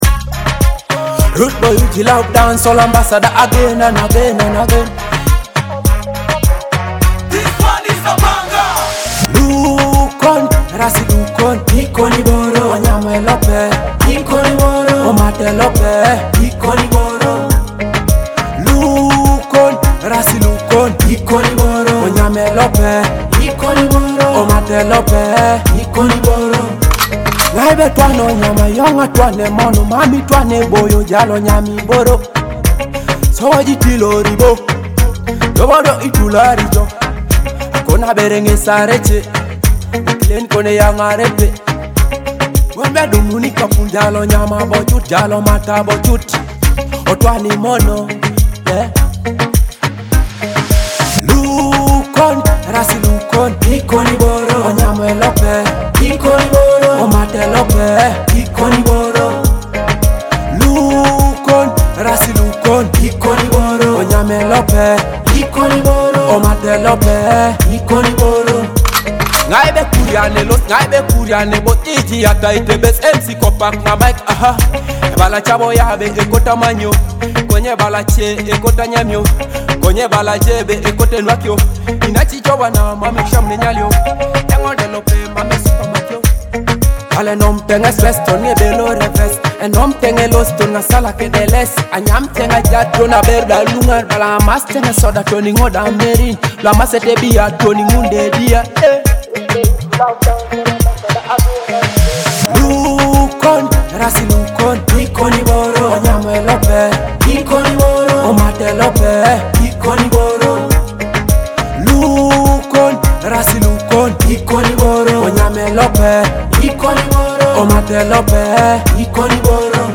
Teso music dancehall